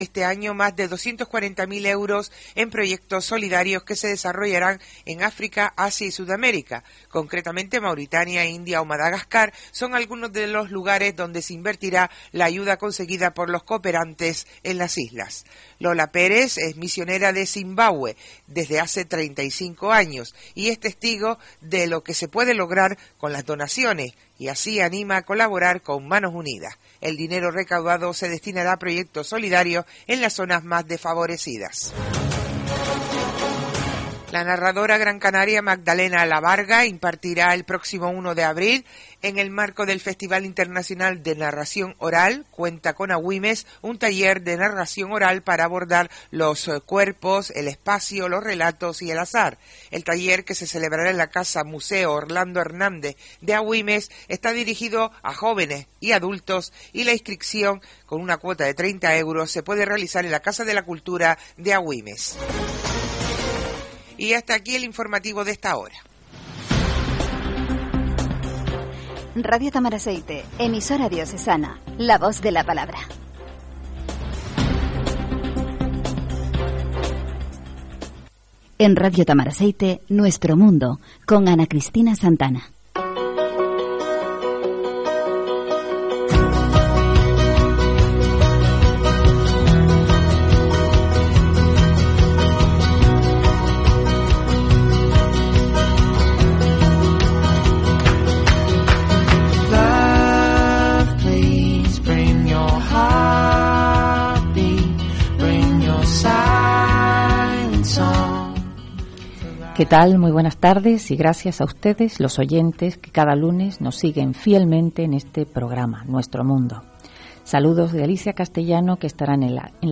Entrevista a Marta Robles en radio Tamaraceite